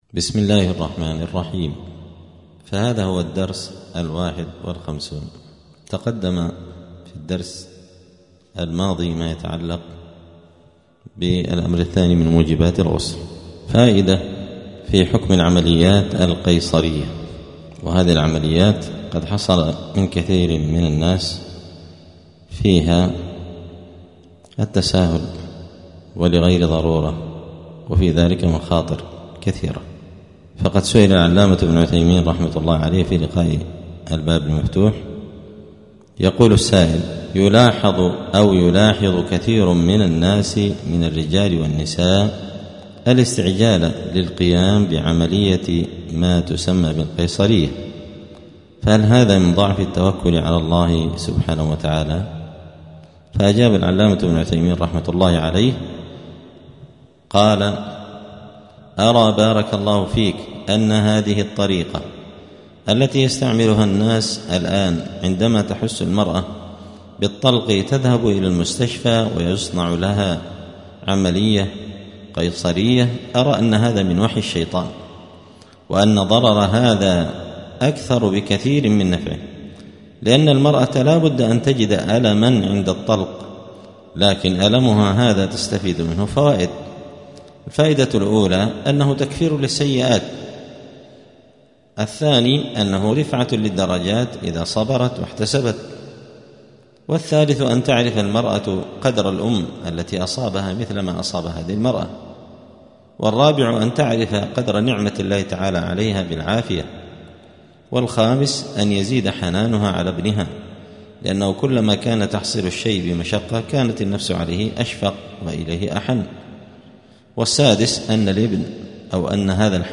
*الدرس الحادي والخمسون (51) {كتاب الطهارة باب موجبات الغسل وصفته موت غير الشهيد}*